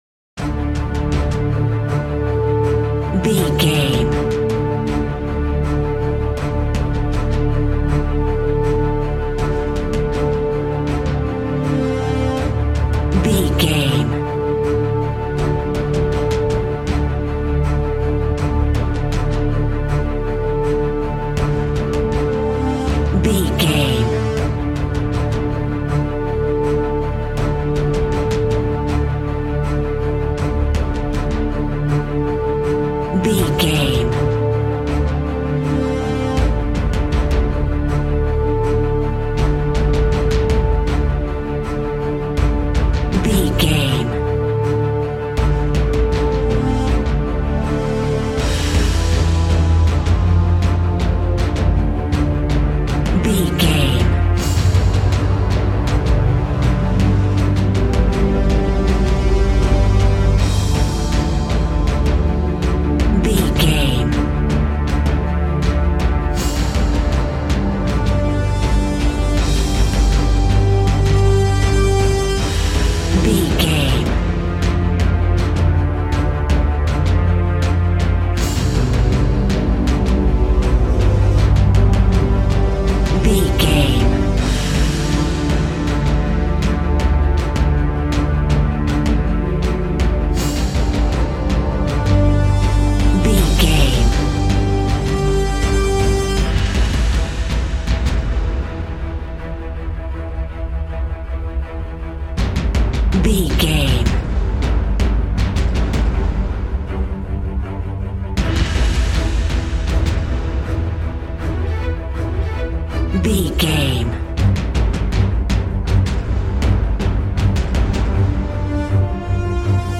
Epic / Action
Fast paced
In-crescendo
Uplifting
Ionian/Major
strings
brass
percussion
synthesiser